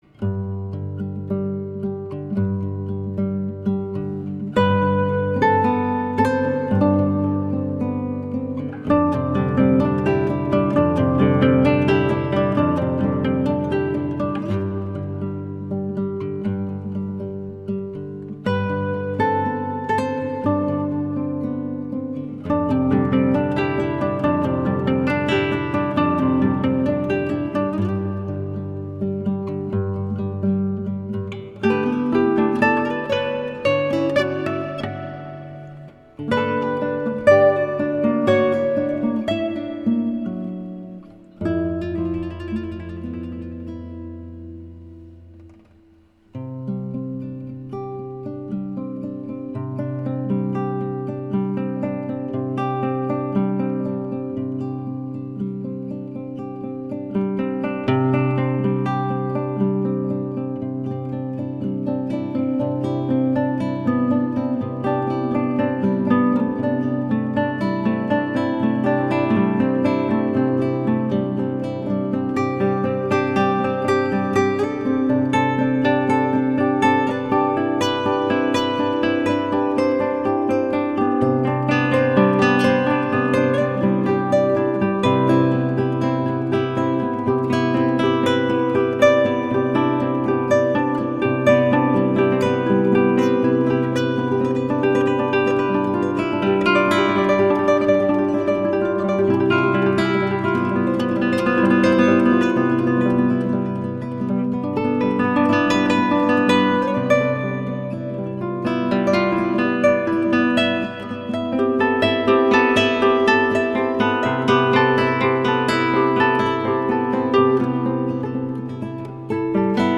20 Guitar Interlude _ Autumn Morning